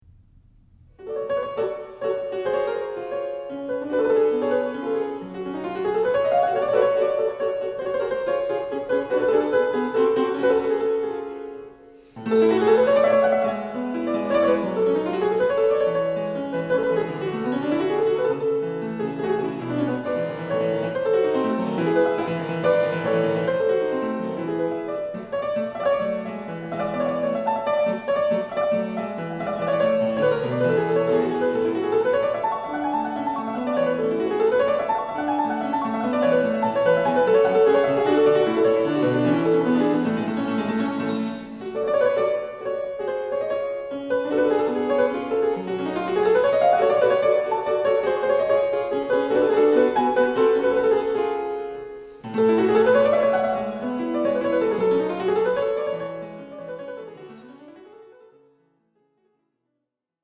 Registration: October 2008, Basel.